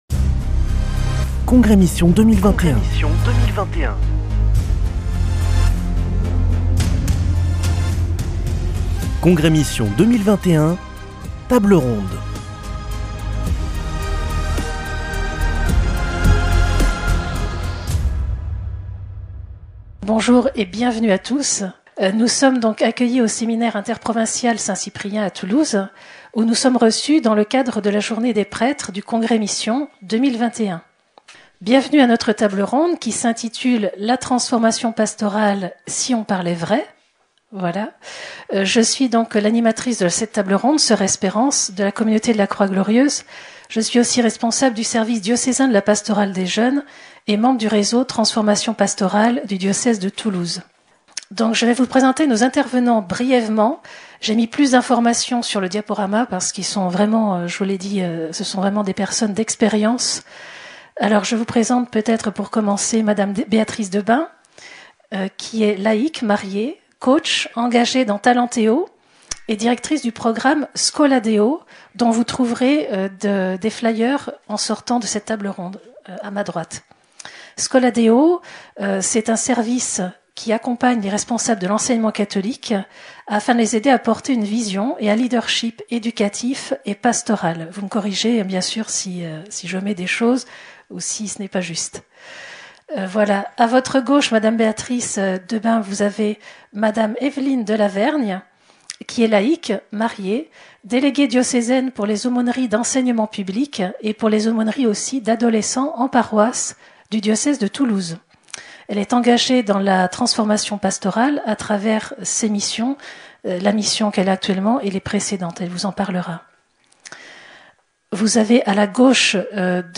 Congrès Mission du 1er au 3 octobre à Toulouse - Table ronde 7